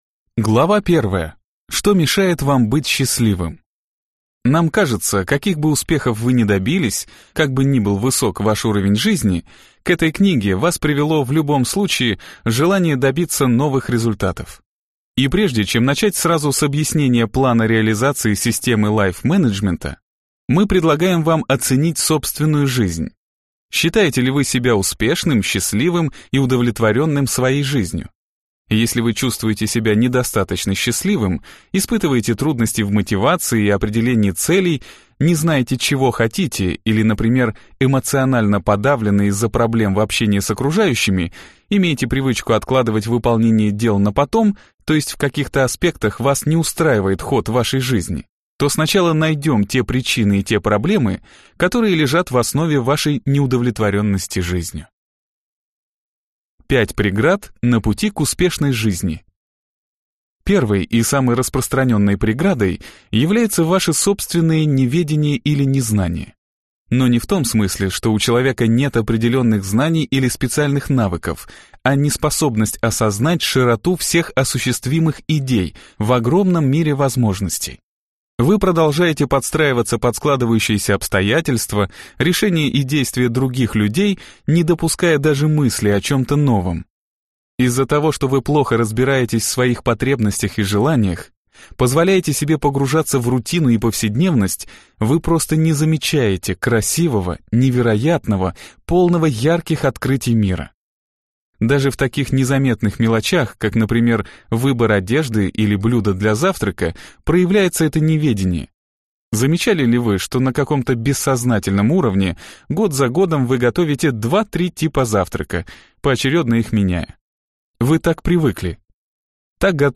Аудиокнига Лайф-менеджмент. Искусство управлять своей жизнью | Библиотека аудиокниг